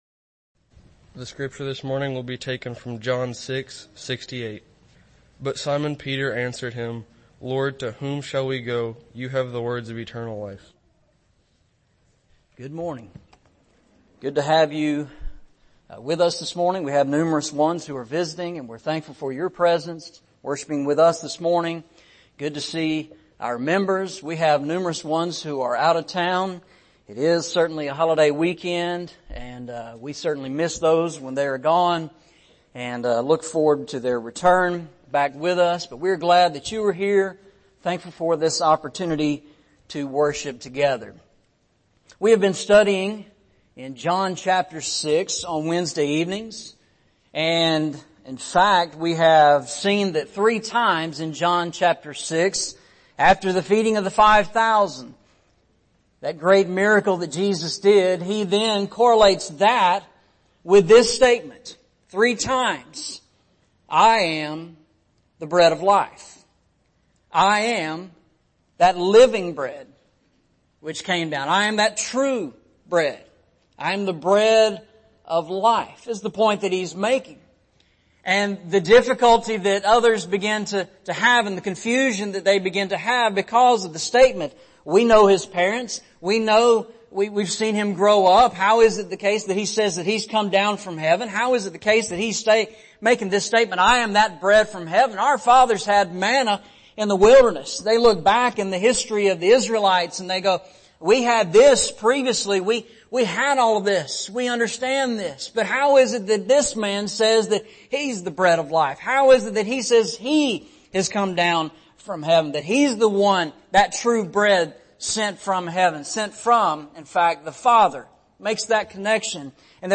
Series: Eastside Sermons